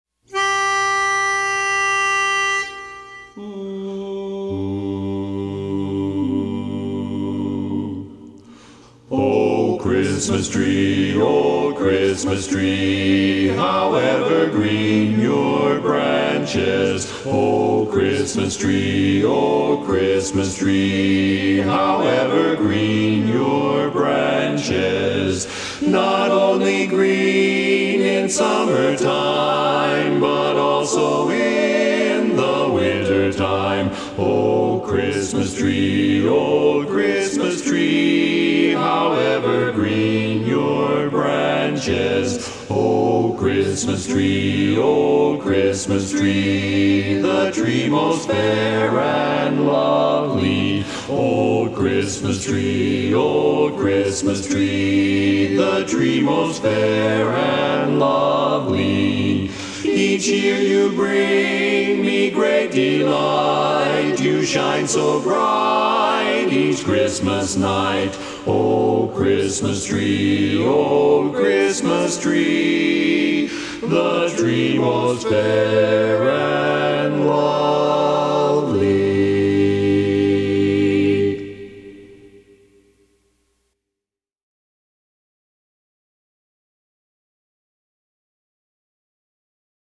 Christmas Songs
Barbershop
Bari